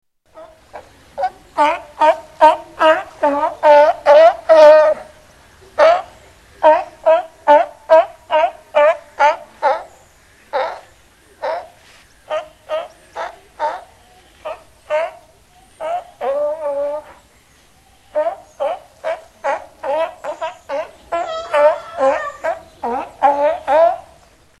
Californian Sealion
Tags: Science and Nature Wildlife sounds Bristish Animals British Wildlife sounds United Kingdom